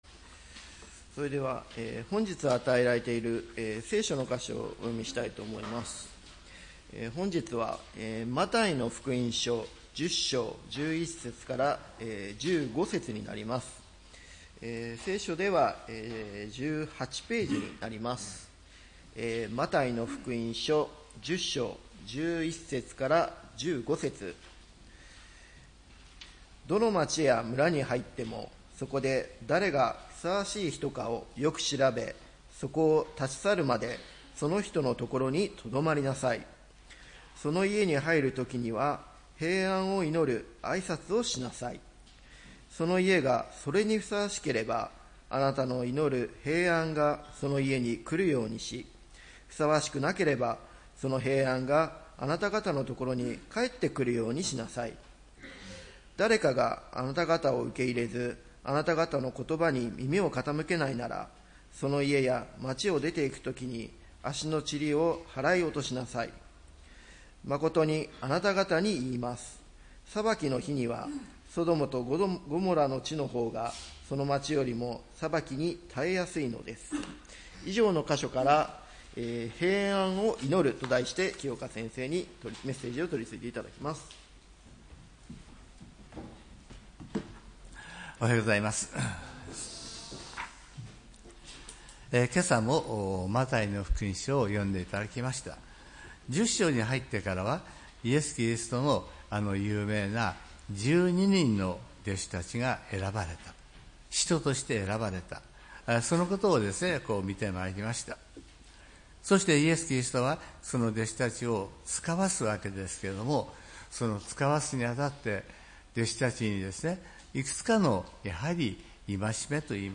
礼拝メッセージ「平安を祈る」（３月８日）